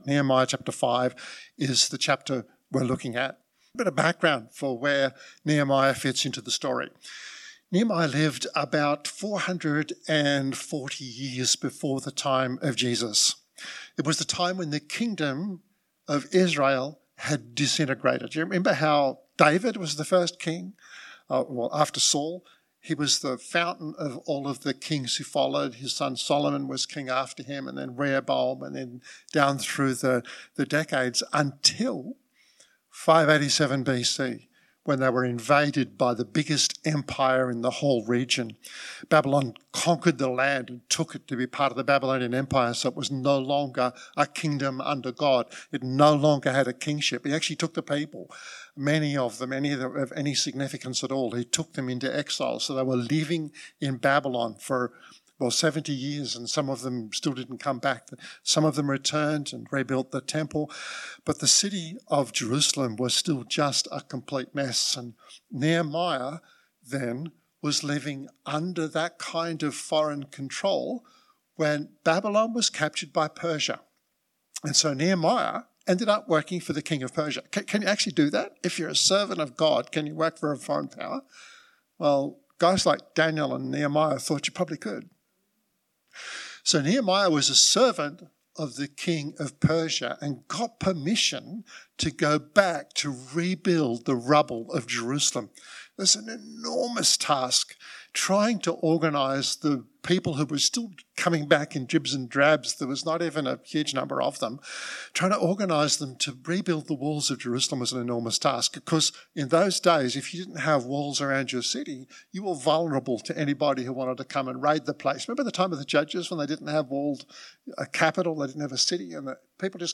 This podcast (27 minutes) was recorded at Riverview Joondalup 2022-06-12.